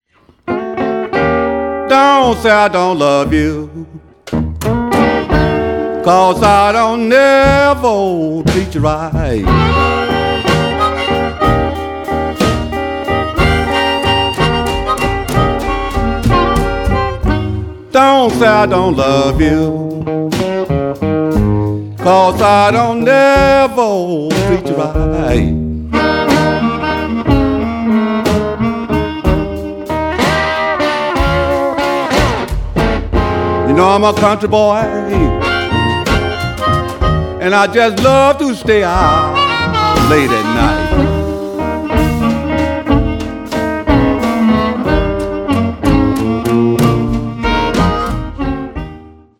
traditional blues